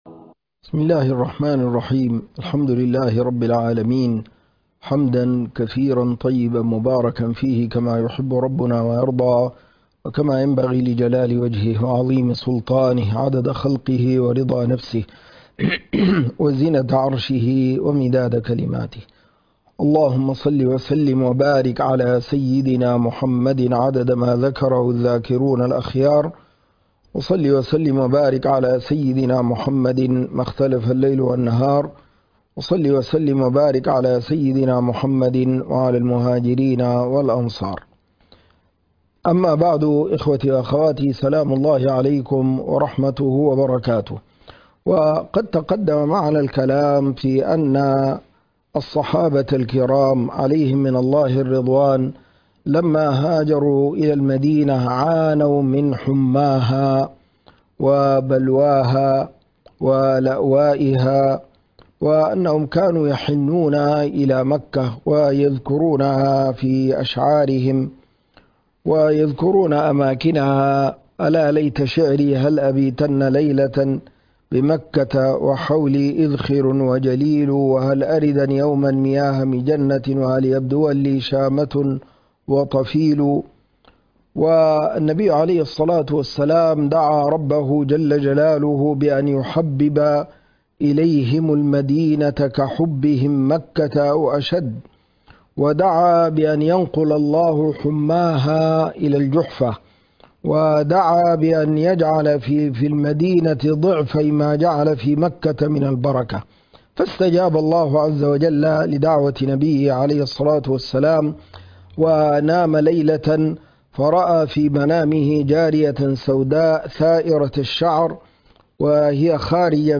السيرة النبوبة .. الدرس 70 حالة المدينة السياسية والإجتماعية بعد الهجرة